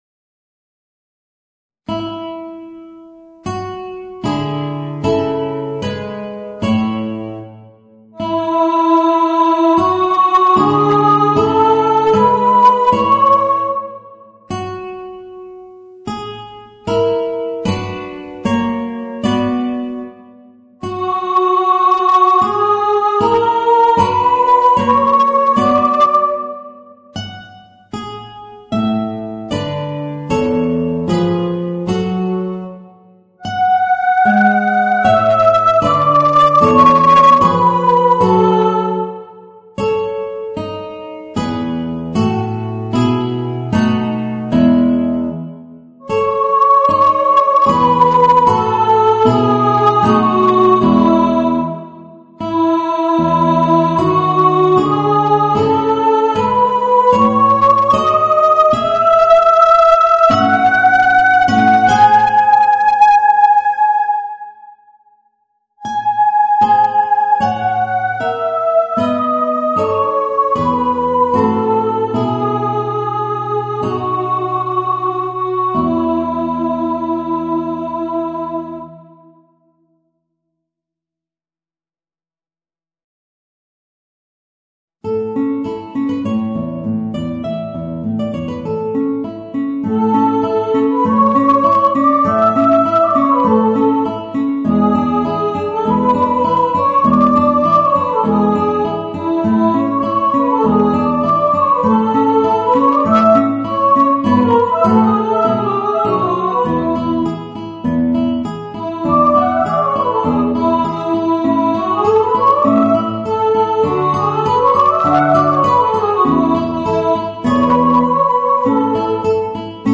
Voicing: Guitar and Soprano